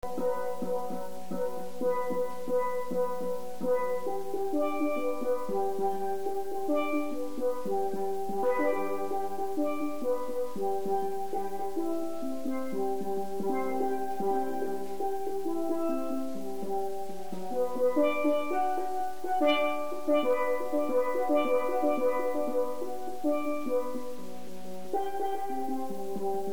Double Second Pan
Our Double Second has 31 notes and a 9" skirt.
The almost 2 1/2 octaves has a register similar to the Double Tenor, but with a deeper, warm, mellow voice.
Range = Violas = Alto